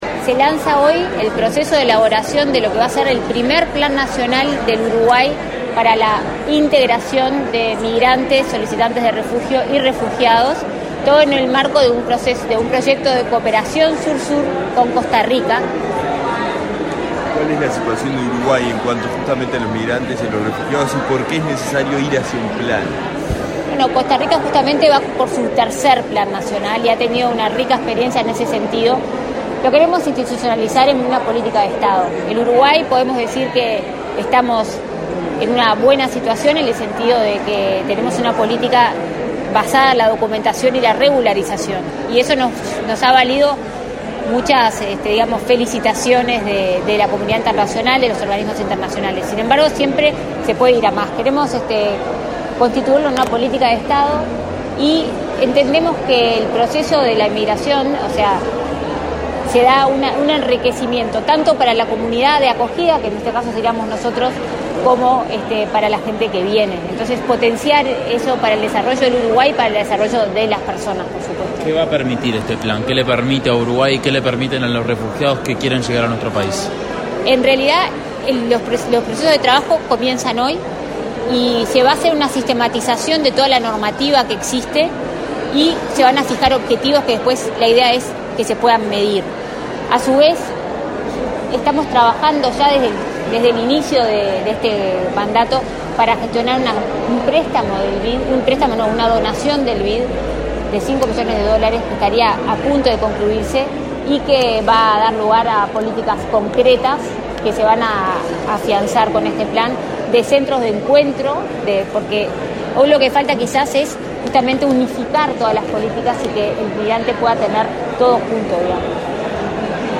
Declaraciones a la prensa de la subsecretaria de Relaciones Exteriores y presidenta de la JNM, Carolina Ache Batlle
La Junta Nacional de Migración (JNM) presentó el Primer Plan de Integración de Personas Migrantes y Refugiadas, para garantizar que estas poblaciones se inserten en Uruguay y promover que contribuyan al desarrollo nacional. Tras el evento, Carolina Ache Batlle, efectuó declaraciones a la prensa.